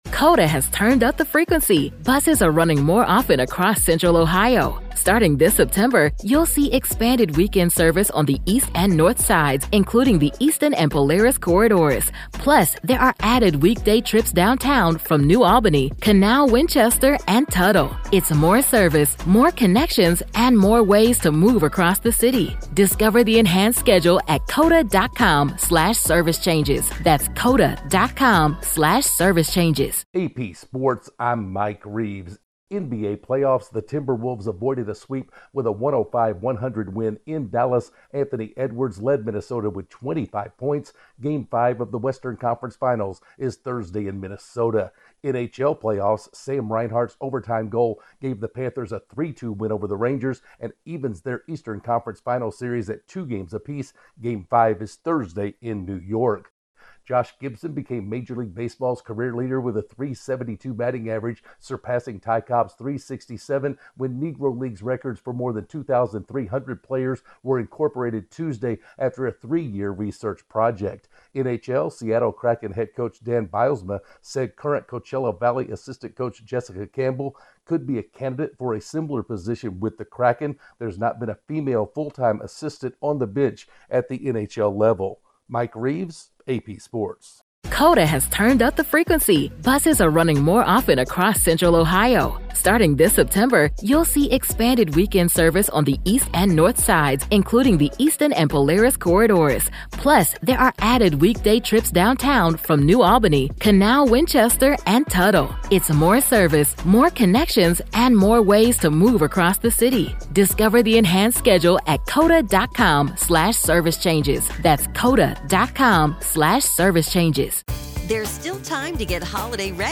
The NBA and NHL playoffs continue, the Major League Baseball record book will have a different look, and the Seattle Kraken are considering a history making assistant coach hire. Correspondent